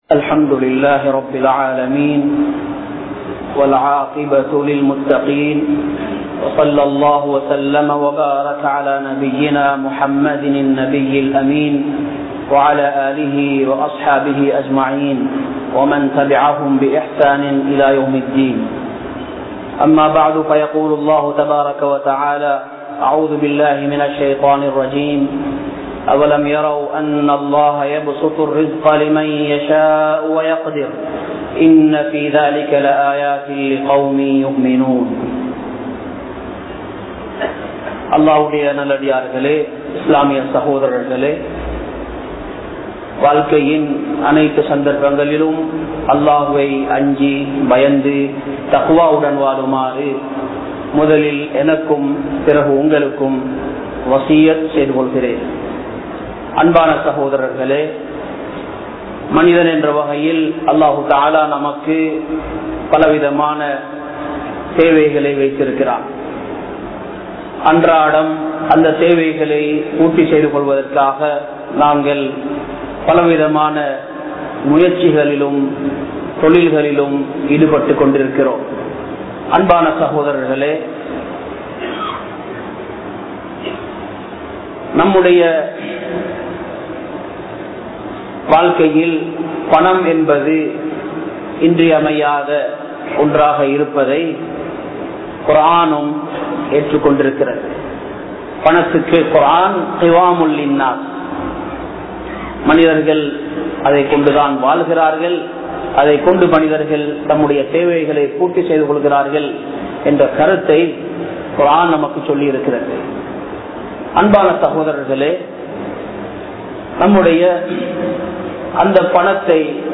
Islam Koorum Viyafara Olunguhal (இஸ்லாம் கூறும் வியாபார ஒழுங்குகள்) | Audio Bayans | All Ceylon Muslim Youth Community | Addalaichenai
Kollupitty Jumua Masjith